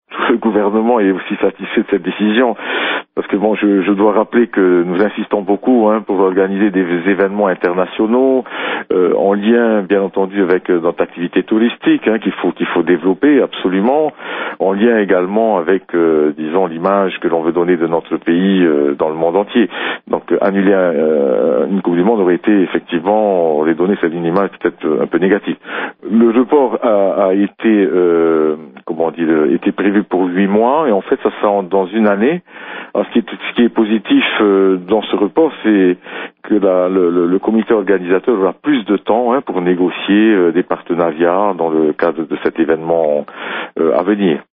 Ministre des Sports, Michel Leboucher se félicite du soutien de la fédération internationale car il envisageait mal l’annulation d’un tel événement sportif.